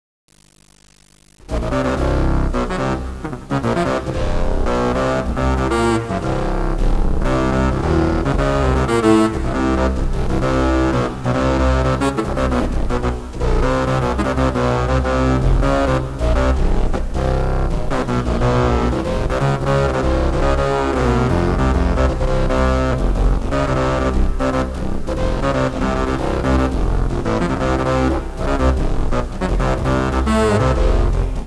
some downloads from the realtime sound generation in the installation motion tracker.die samples der hier zur verfügung stehenden sounds sind ausschließlich durch das verhalten des sensibilisierten bildes und des raumes erzeugt worden. auf eine nachträgliche manipulation der sounds wurde verzichtet, da innerhalb des projektes nur eine akzeptanz interaktiv erzeugter klänge vorhanden ist.
die ersten testaufnahmen für motion tracker fanden im hamburger gvoon studio im dezember 1996 statt. the tracks and some downloads in low quality 8 bit 22 khz, type aiff, 30 sec.: track one: total running 40:12 min.